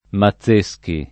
[ ma ZZ%S ki ]